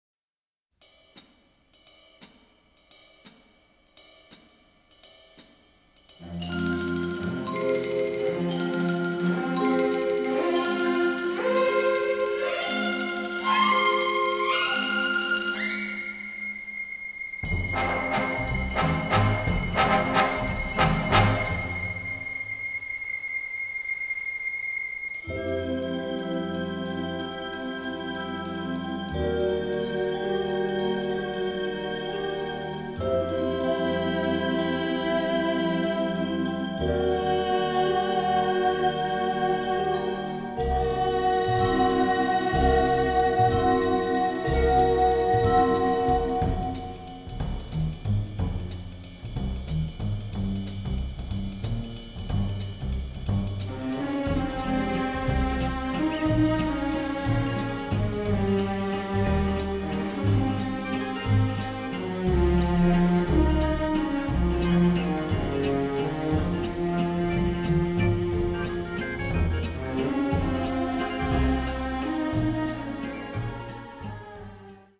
vibrant, funny, powerfully melodic
The recording took place at CTS Studios in Bayswater